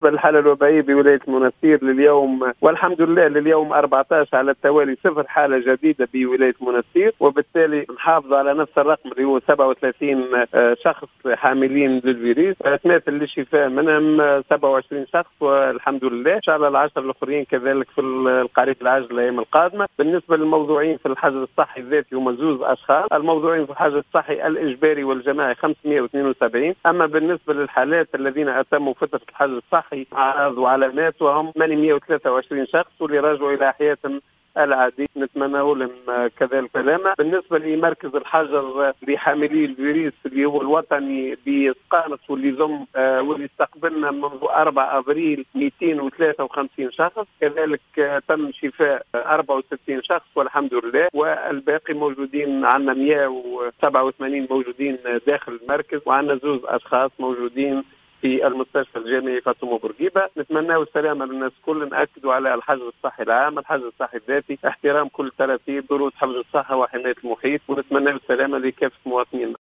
أكد المدير الجهوي للصحة بالمنستير، المنصف الهواني، في تصريح اليوم لـ"الجوهرة أف أم"، عدم تسجيل إصابات بفيروس كورونا منذ 14 يوما.